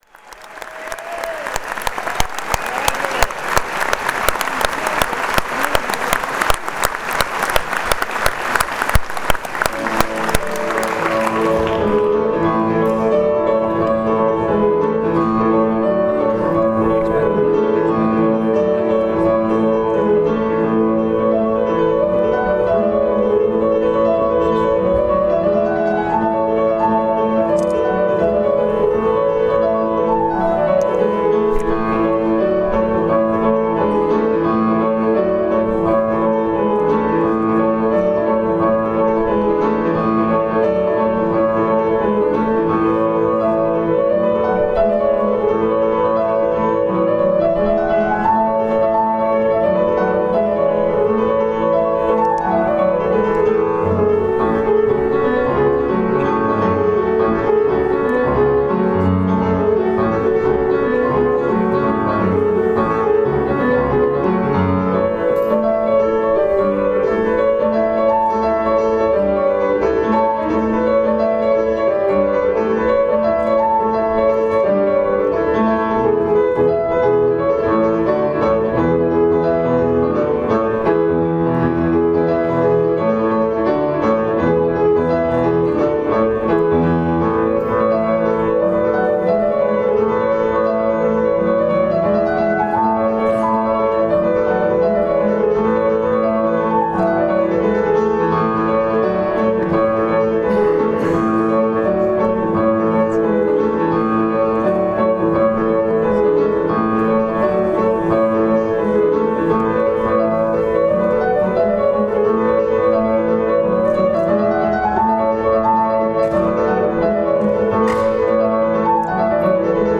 Il teatro è assai grazioso, e anche molto piccolo.
PIANO SOLO